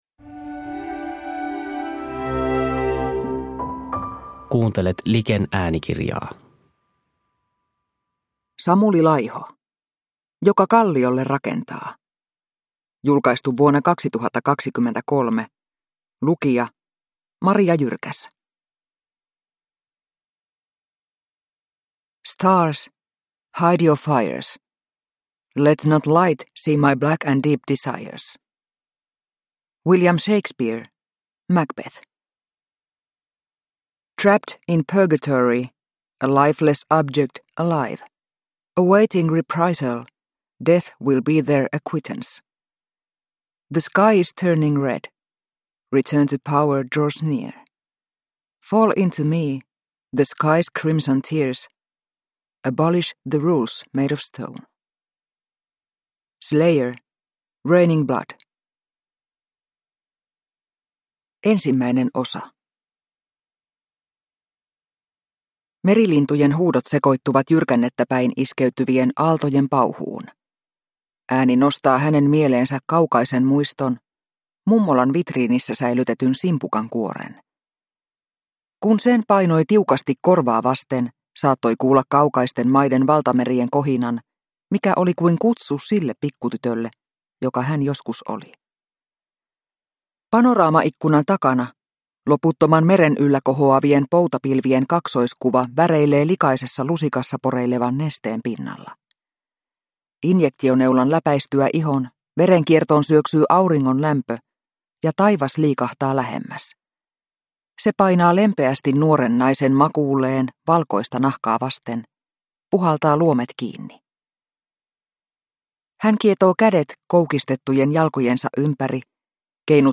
Joka kalliolle rakentaa – Ljudbok – Laddas ner